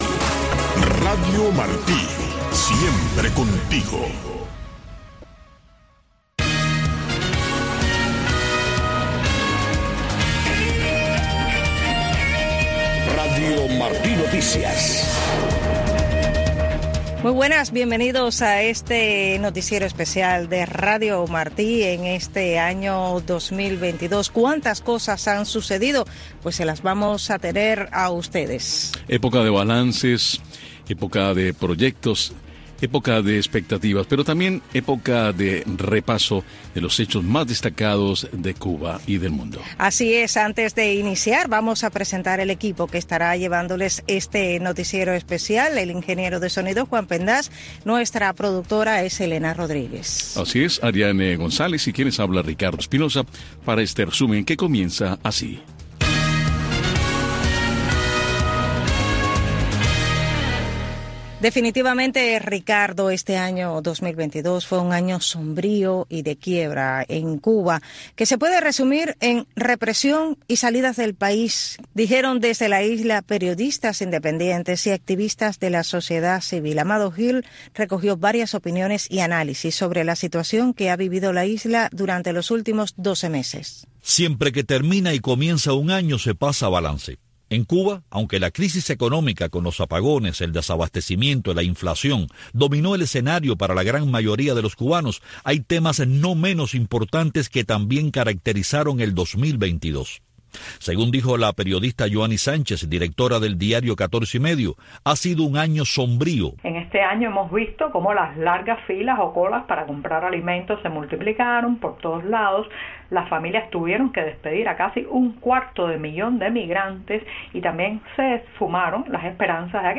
Noticiero de Radio Martí 5:00 PM | Primera media hora